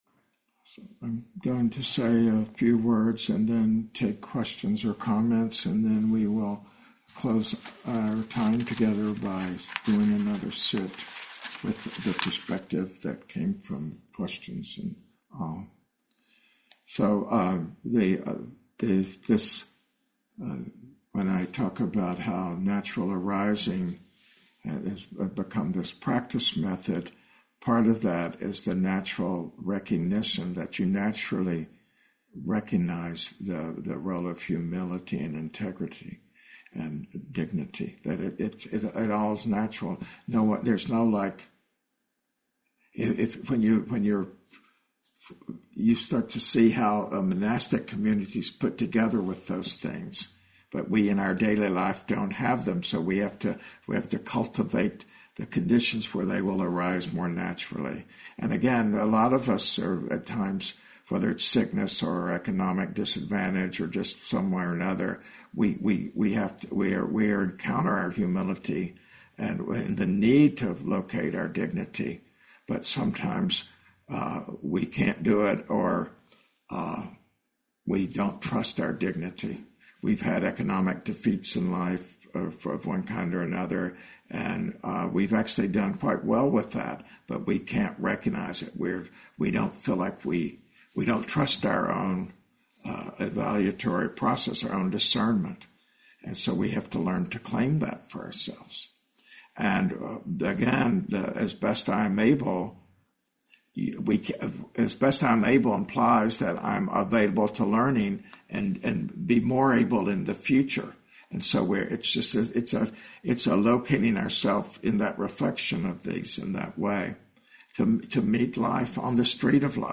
Buddhist Meditation Group